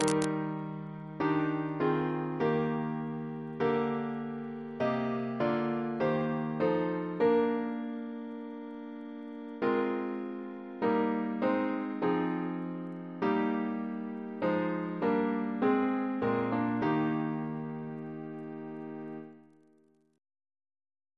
Double chant in E♭ Composer: Chris Biemesderfer (b.1958)